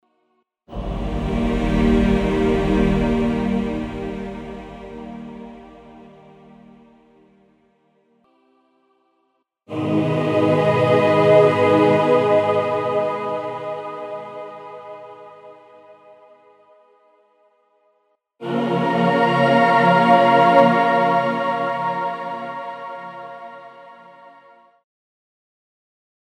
Здесь собраны необычные звуковые композиции: от тонких природных мотивов до абстрактных эффектов, способных вызвать яркие ассоциации.
1. Прозрел (три хоровых варианта) n2. Прозрел (три версии хора) n3. Прозрел (три хоровых исполнения)